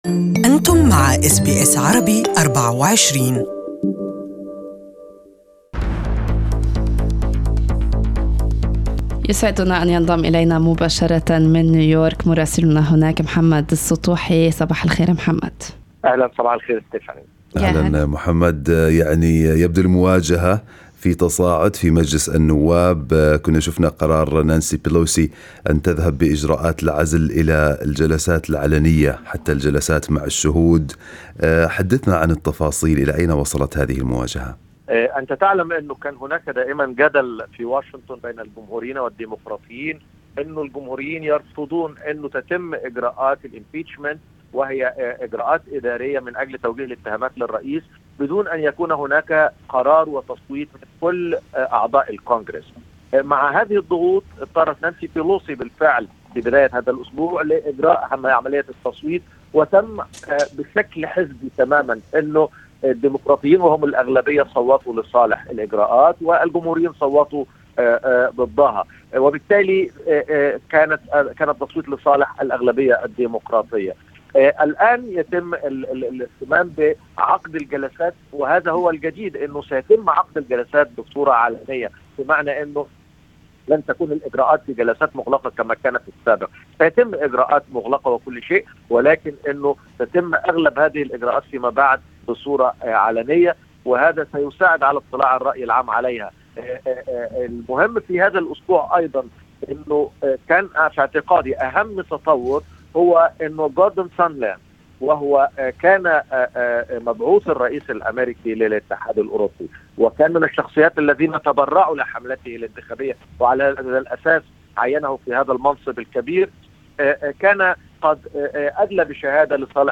Listen to the full report from our NYC correspondent in Arabic above Share